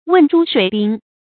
問諸水濱 注音： ㄨㄣˋ ㄓㄨ ㄕㄨㄟˇ ㄅㄧㄣ 讀音讀法： 意思解釋： 濱：水邊。